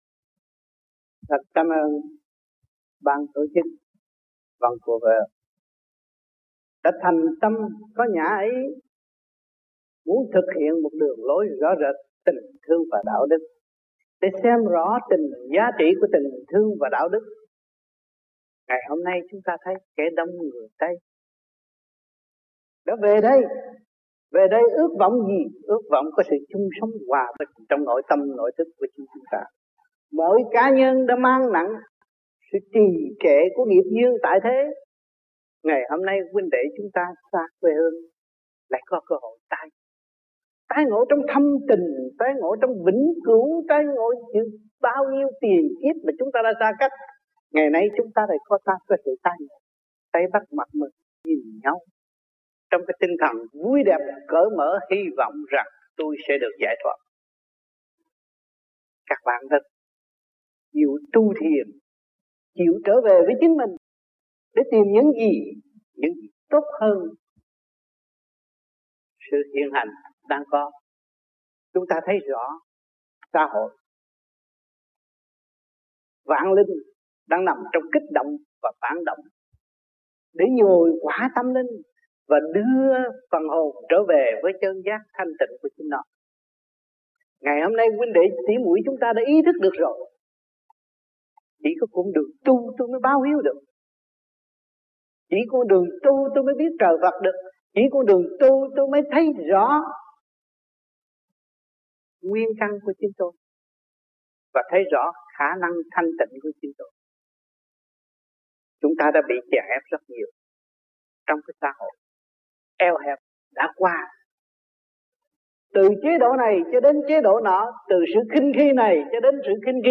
1986-07-04 - VANCOUVER - ĐHVVQT KỲ 5 - HUẤN TỪ KHAI MẠC